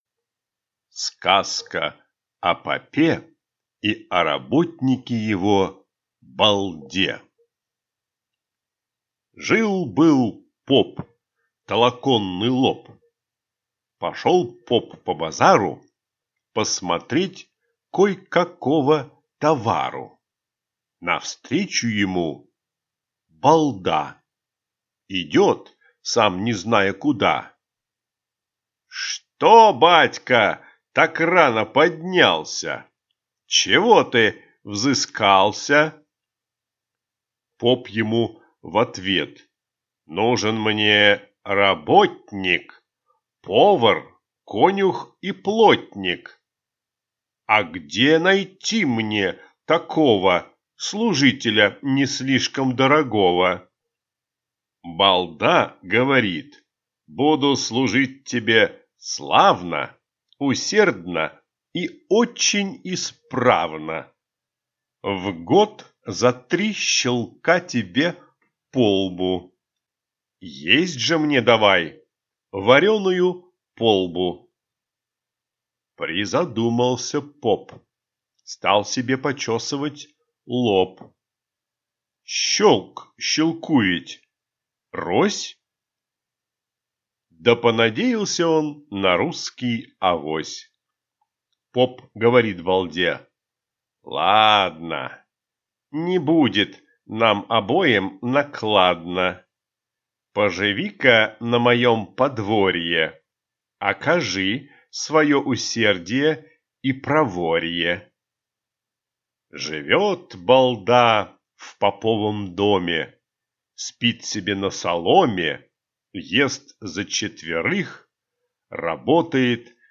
Аудиокнига Сказки русских писателей | Библиотека аудиокниг